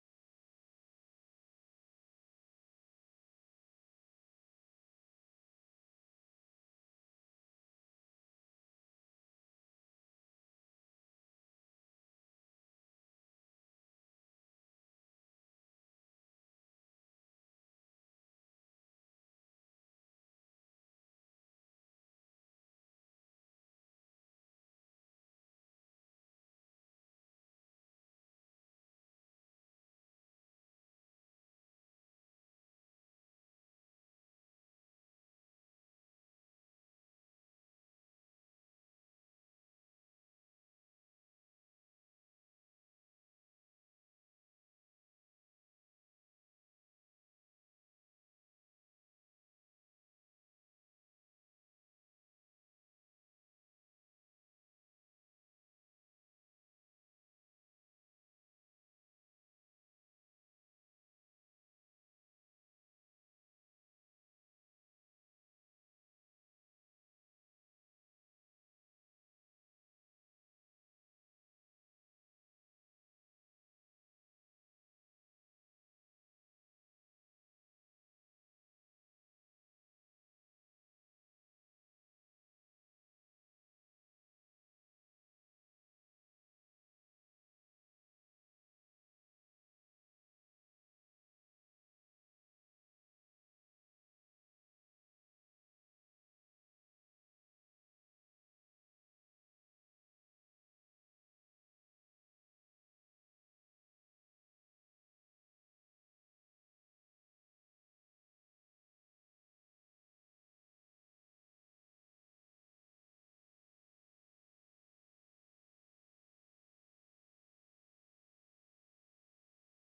Final Sunday sermon of 2024